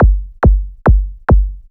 VTDS2 Song Kit 04 Rap When I Come Around Kick.wav